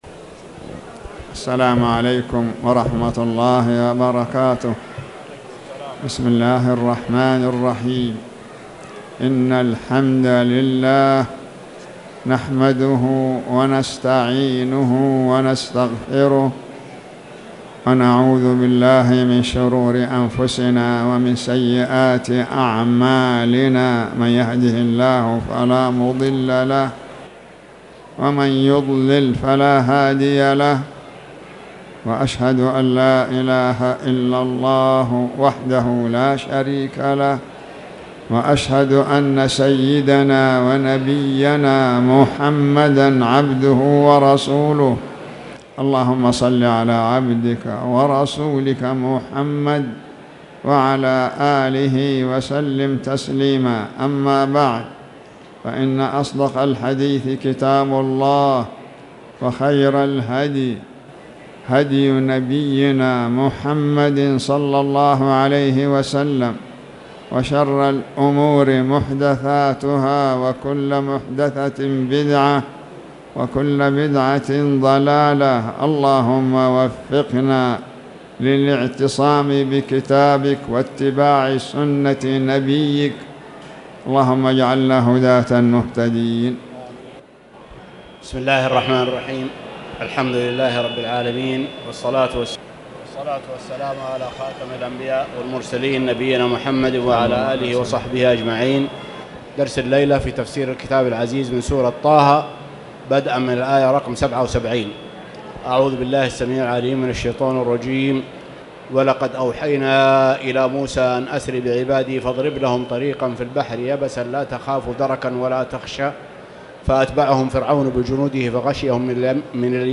تاريخ النشر ٨ رجب ١٤٣٨ هـ المكان: المسجد الحرام الشيخ